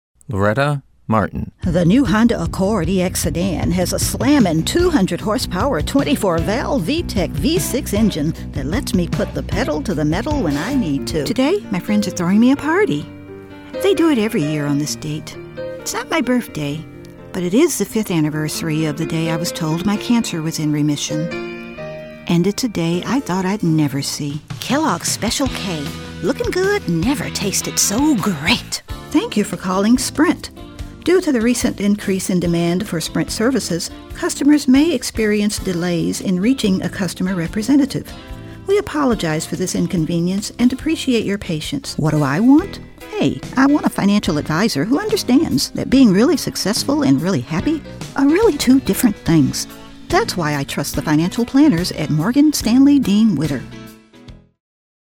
Medium to upper register. Middle America, Southern "Dixie," African American (urban, not "street")
Sprechprobe: Werbung (Muttersprache):
Natural warmth, conversational, quirky, mature, corporate, cosmopolitan or folksy